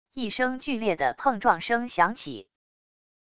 一声剧烈的碰撞声响起wav音频